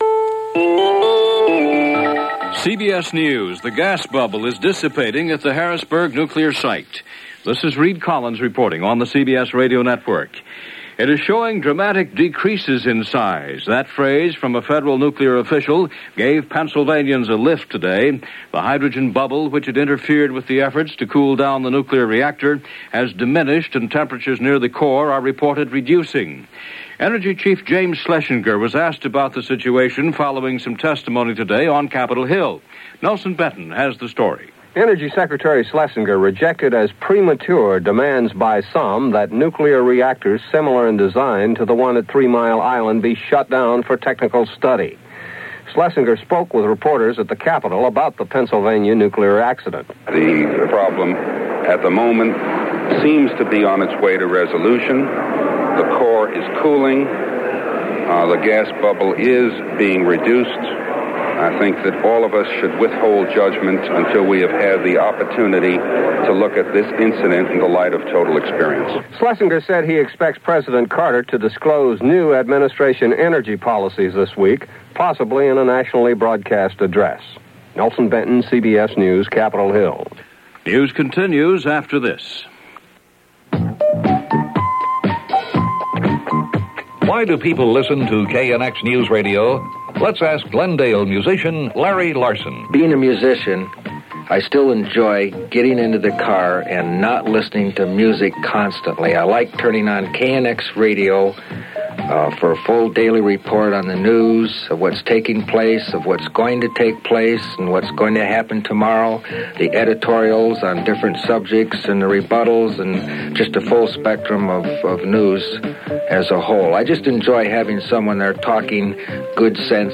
April 2, 1979 – CBS Hourly News – Gordon Skene Sound Collection –
And that’s a little of what went on, this April 2nd 1979 as reported by CBS Radio Hourly news.